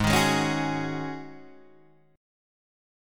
G#7sus4 Chord